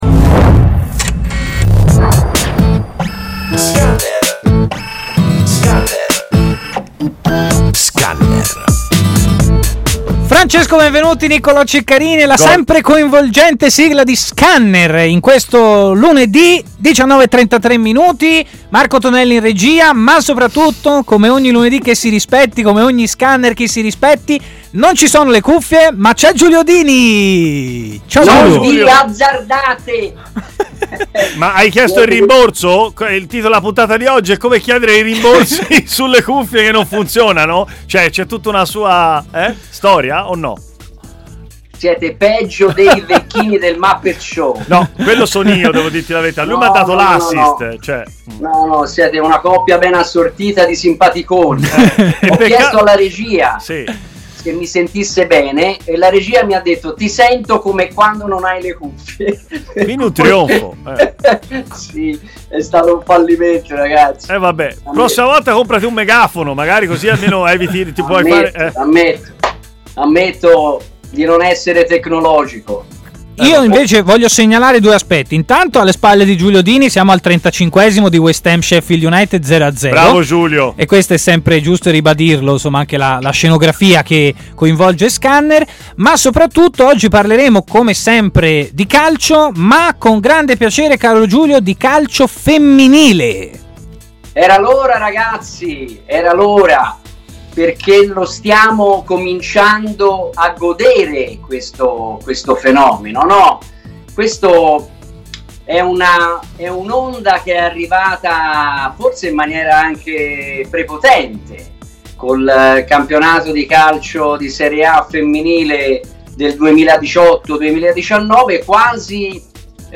è intervenuto in diretta a TMW Radio, nel corso della trasmissione Scanner, per approfondire la situazione del calcio femminile italiano